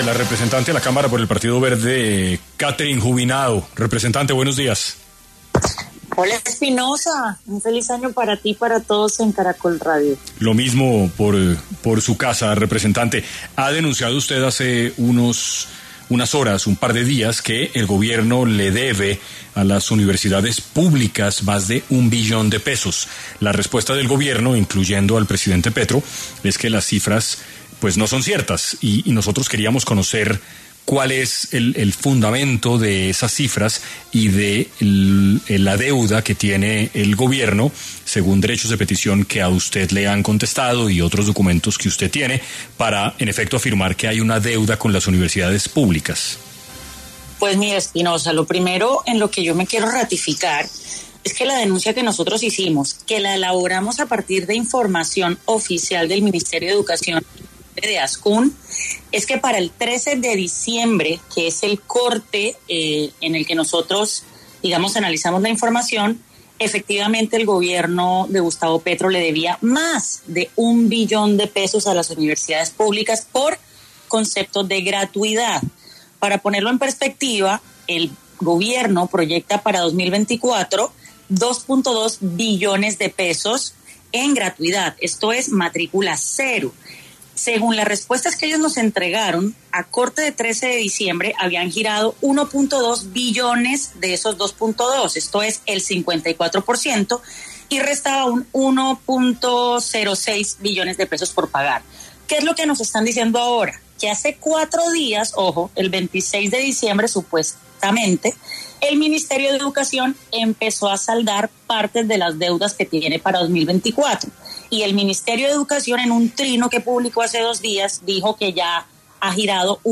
En Caracol Radio estuvo Catherine Juvinao, representante a la Cámara por el partido Alianza Verde.